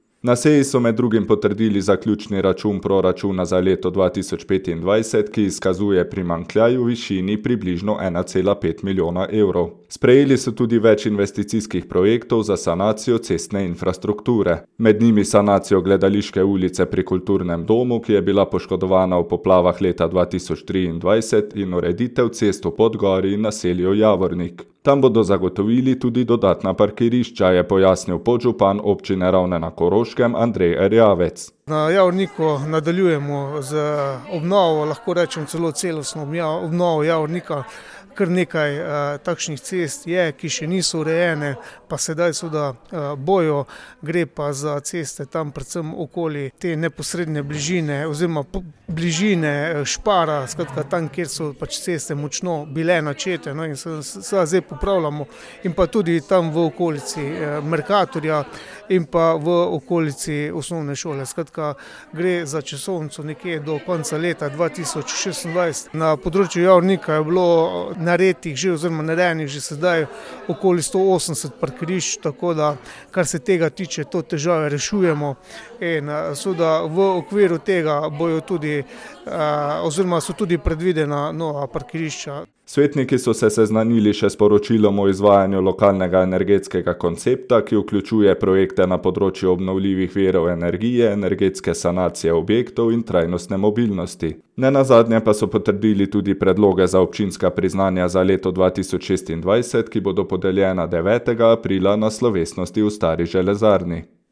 Tam bodo zagotovili tudi dodatna parkirišča, je pojasnil podžupan Občine Ravne na Koroškem Andrej Erjavec.